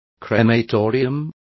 Complete with pronunciation of the translation of crematoriums.